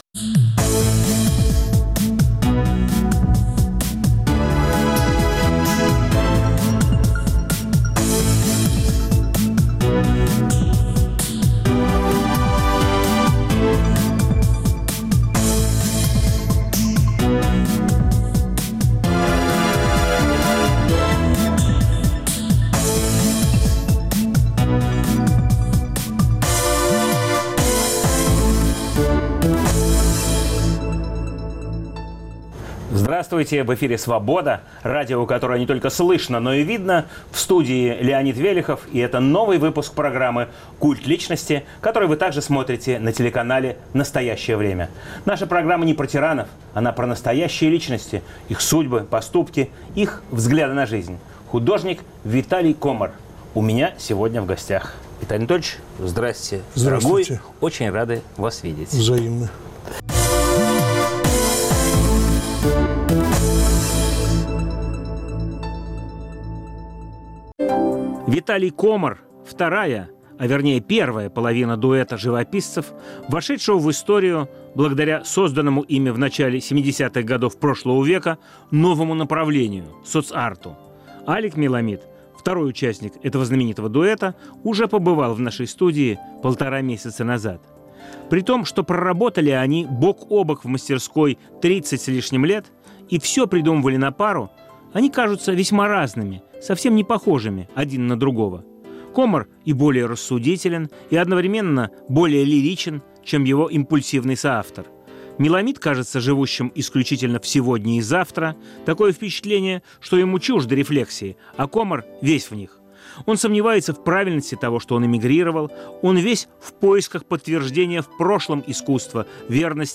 Новый выпуск программы о настоящих личностях, их судьбах, поступках и взглядах на жизнь. В студии художник Виталий Комар.